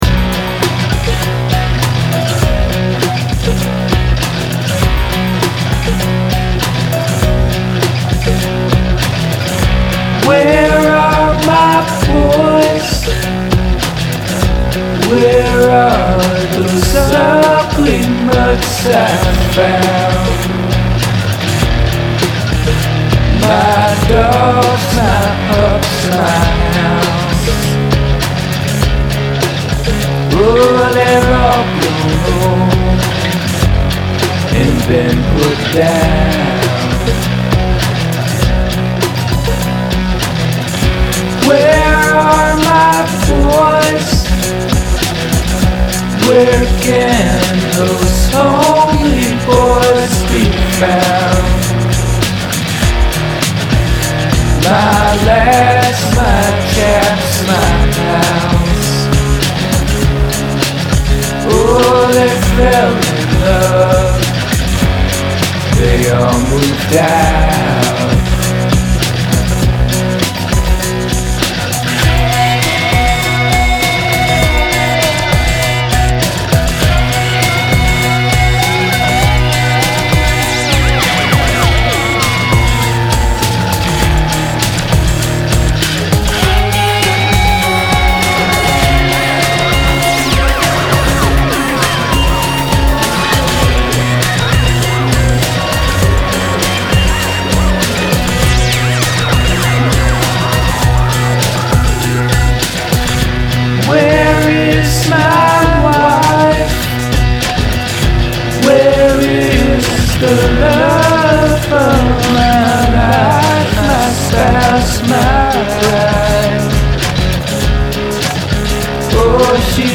Verse: C, G/B, Em, Am, G, D, C, G, C
verse (instrumental)
sort of a silly song. i'm not that happy with this mix. the vocals are too loud and i'm not sure what to do with the guitars. i think the chords and the melody are solid, but the arrangement isn't all that great, and the words are sort of so-so. instead of writing a proper bridge i just did radio type ear candy. i hate it when bands do that, its such lazy songwriting. the guitar is actually my acoustic banging around on power chords and then i put a ton of distortion on it in the computer. that could be why i'm not that into the sounds.
It's groovy, though I am not sure this sound is right for this song.